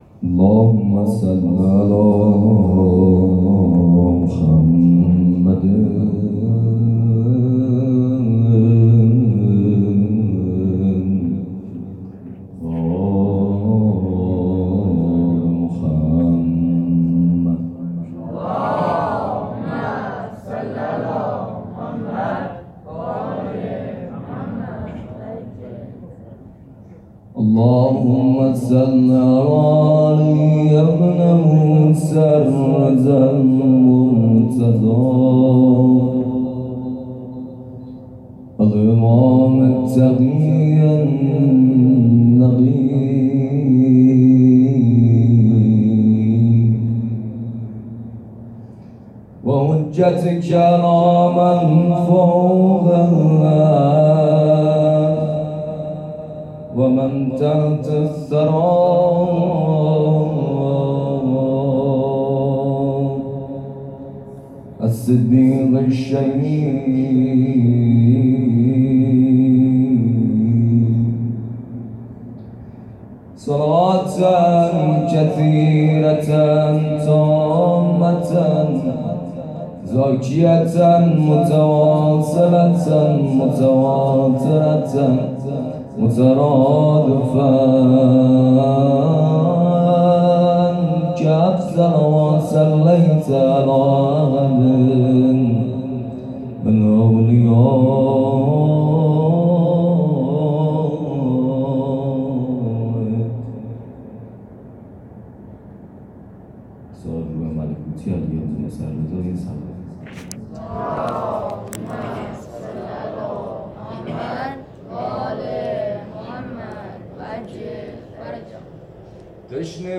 2-مدح-تشنه-بودیم-که-باران-نجف-را-دیدیم.mp3